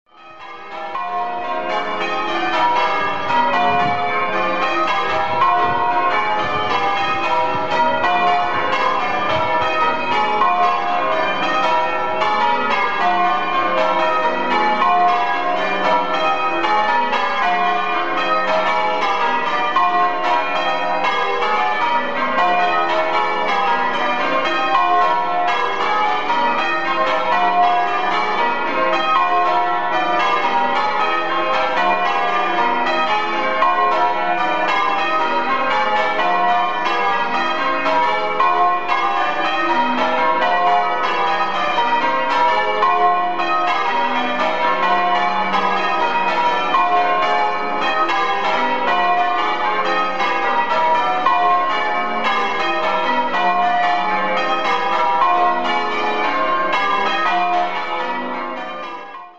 Hear the bells – Ringing Cambridge major